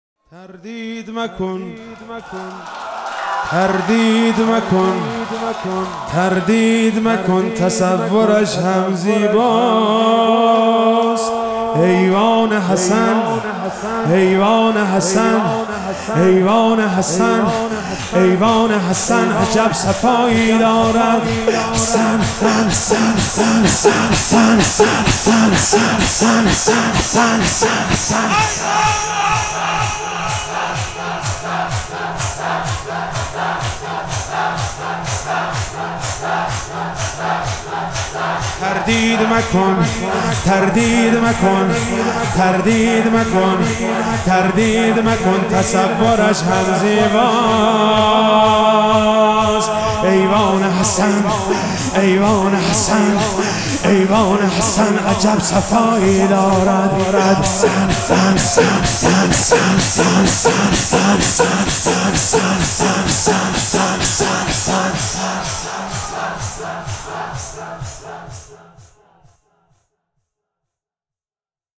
محرم 98